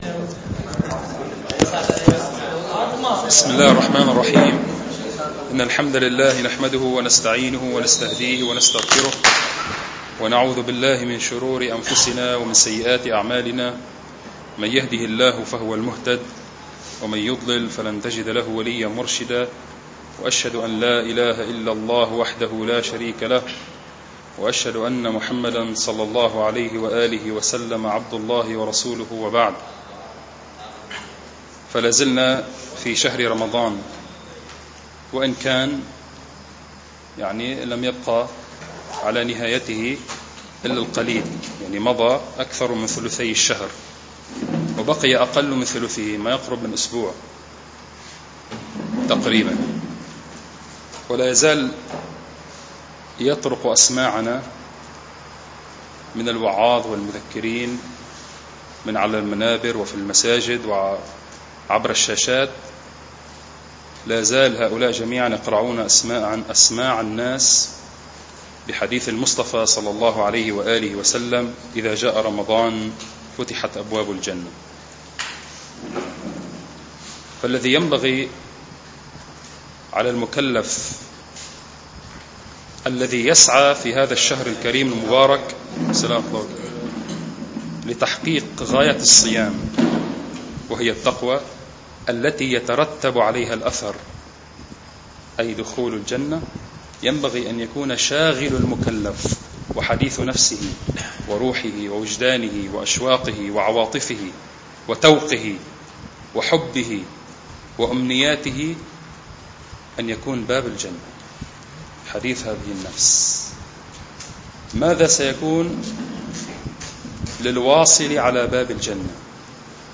المكان : ثانوية المنار الإسلامية